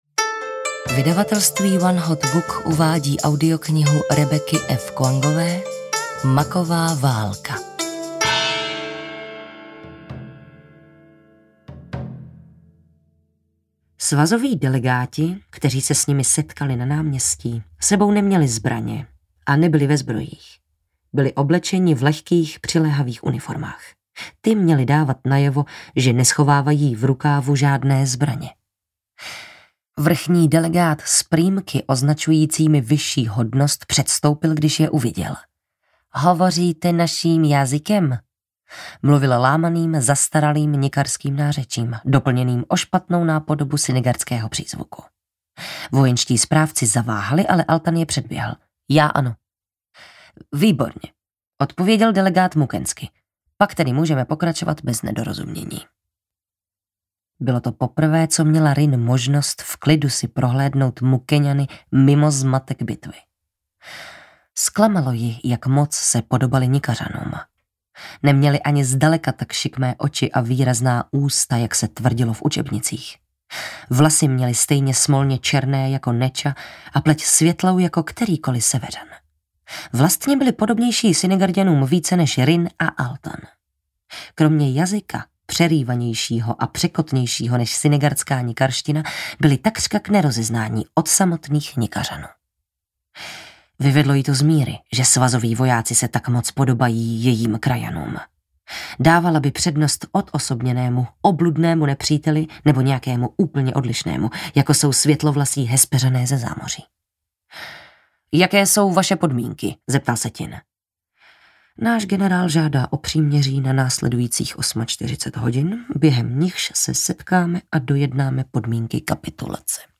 Maková válka audiokniha
Ukázka z knihy